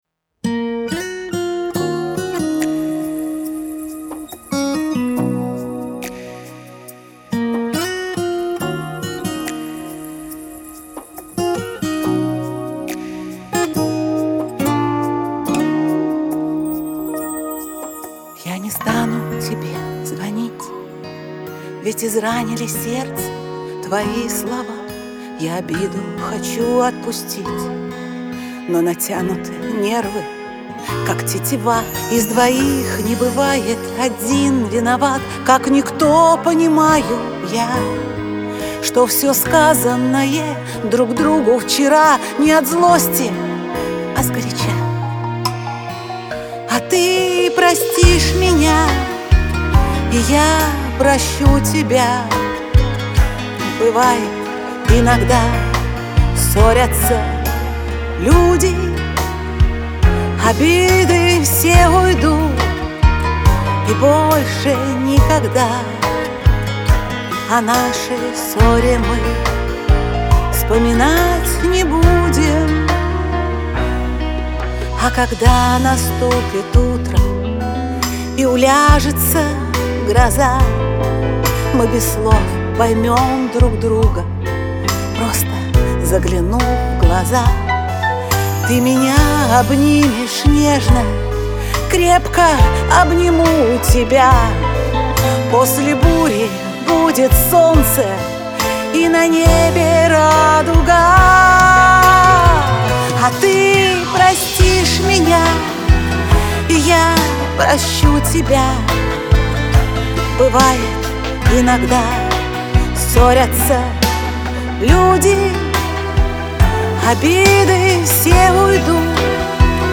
грусть
Лирика